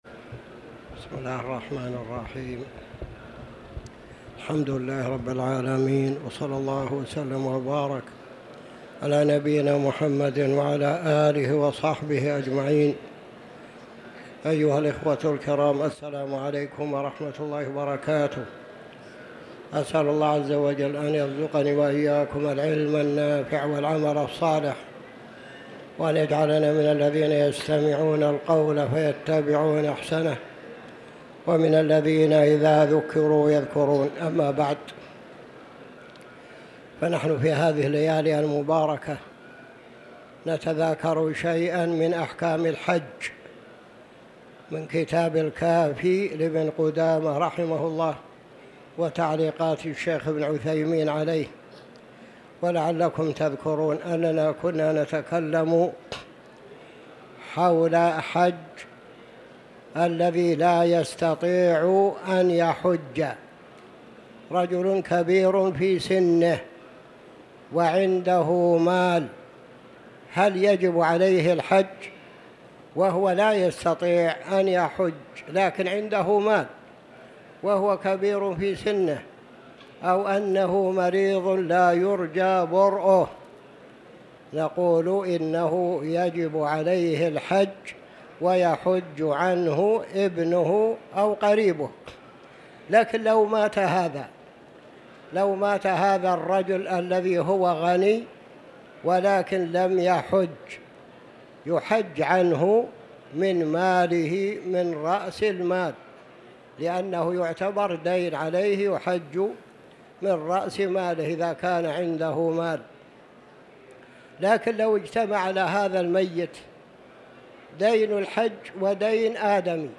تاريخ النشر ٢٥ ذو القعدة ١٤٤٠ هـ المكان: المسجد الحرام الشيخ